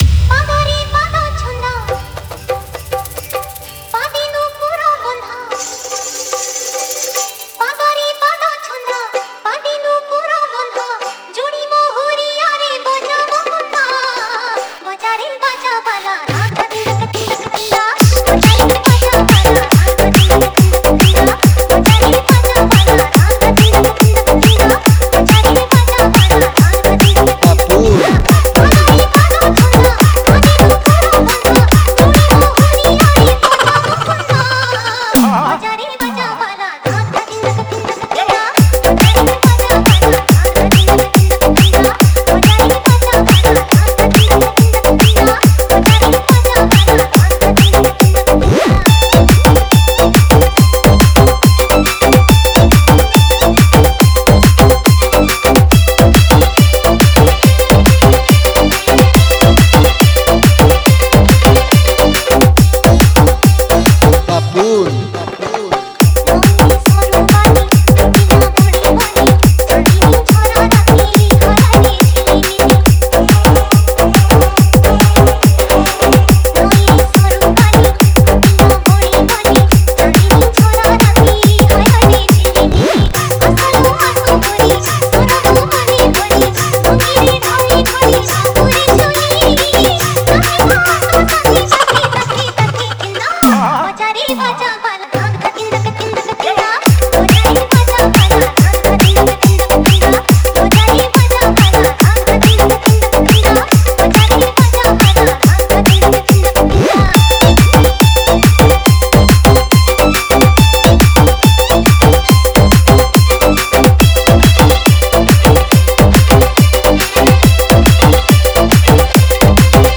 DESI HARD BEAT PUNCH MIX
Category: Holi Special Odia Dj Remix Songs